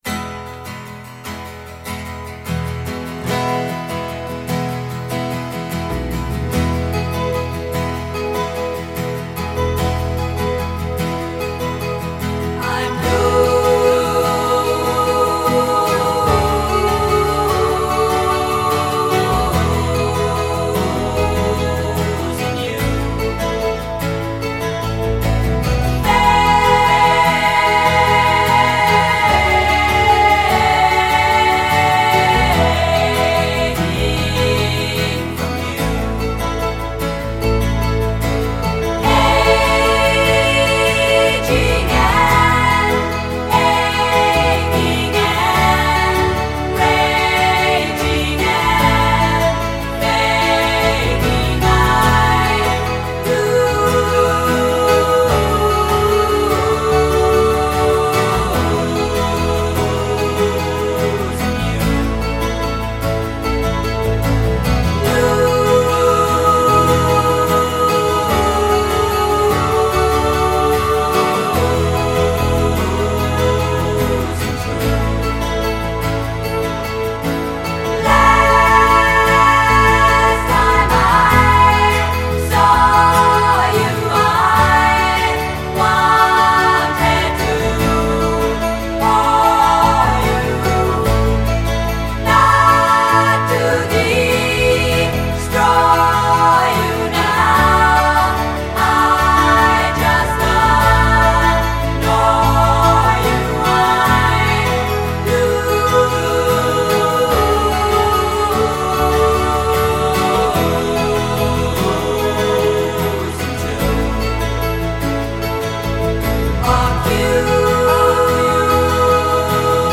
a trio of folk-singing sisters
exquisite harmonies
Guitar solo
This one sounds a little hypnotic